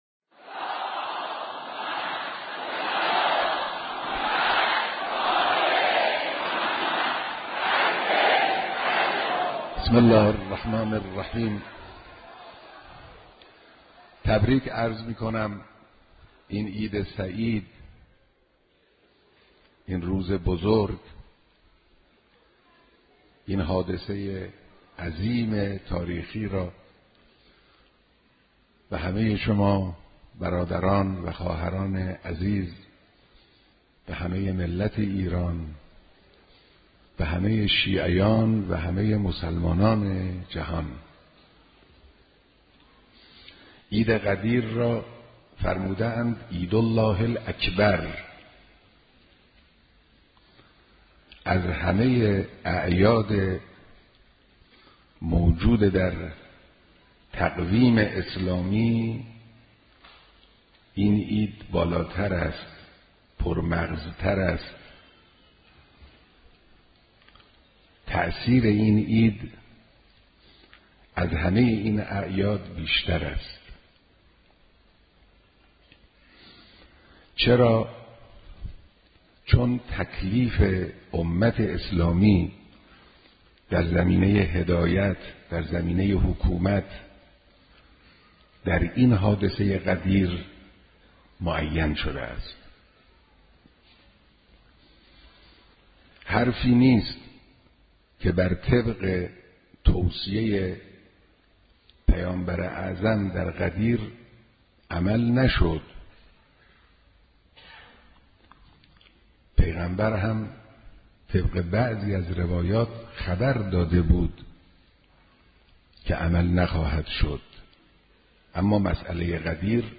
بیانات ارزشمند مقام معظم رهبری (مدظله العالی) در مورد «تبیین فلسفه عید غدیر» ویژه عید امامت و ولایت
سخنرانی